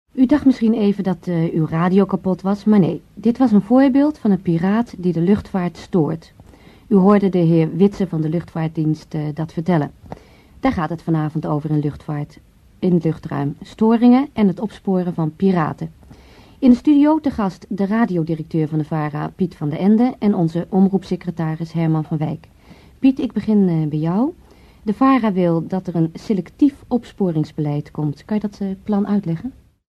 Het betreft een VARA uitzending van het programma Luchtruim uit 1981.
VARA_Stem_1981.mp3